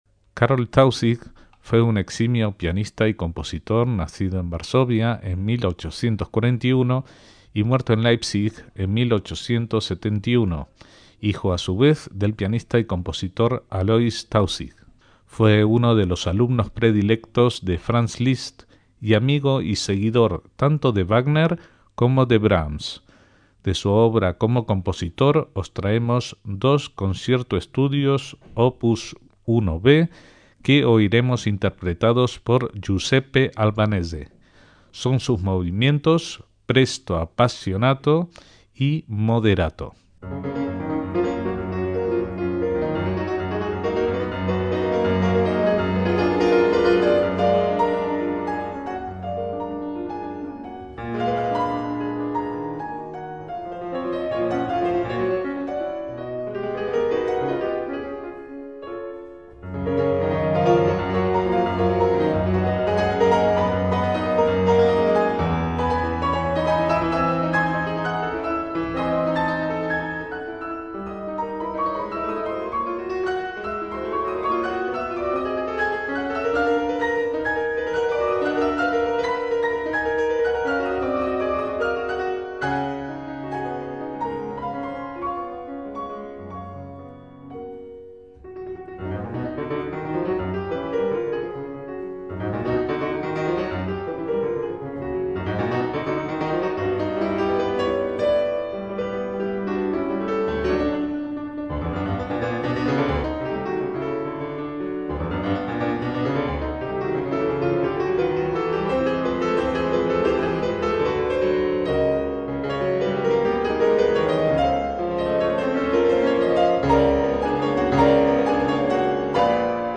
Concierto-Estudios para piano de Karl Tausig
MÚSICA CLÁSICA - Karl (o Carl) Tausig fue un compositor y pianista nacido en Varsovia en 1841 y fallecido antes de cumplir los 30 años de edad en Leipzig.